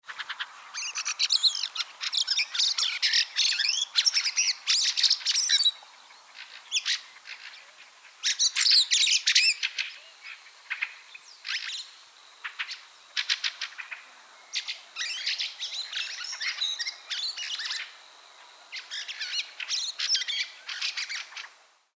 schrrk-schrrk... kurz (?/i)
lang (?/i) Wacholderdrossel
Turdus_pilaris_TSA-medium.mp3